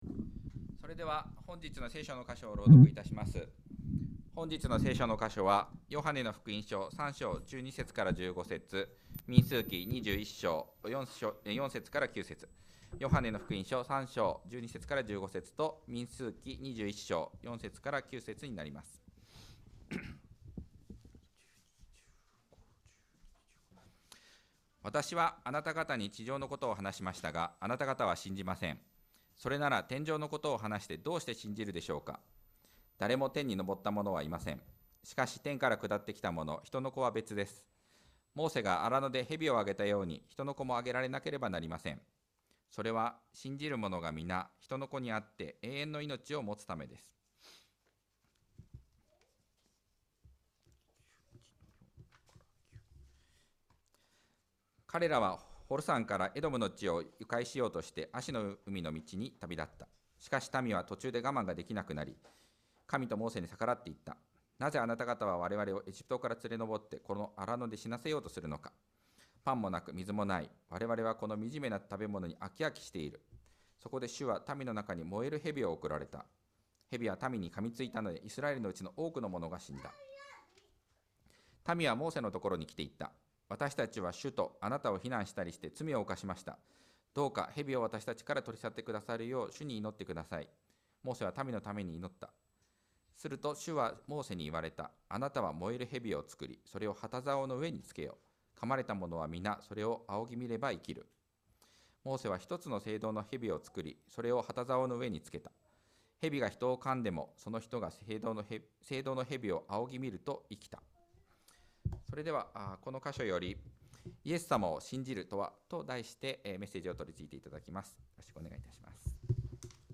2024年11月17日礼拝 説教「イエス様を『信じる』とは？」